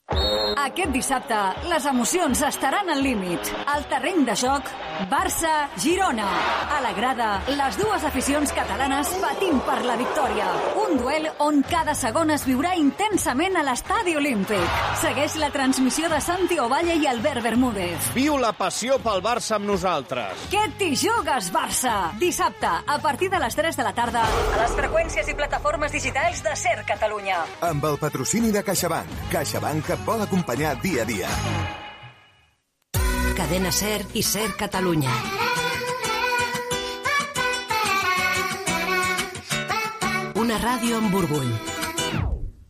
Promoció del programa "Què t'hi juges Barça", indicatiu de SER Catalunya - Cadena SER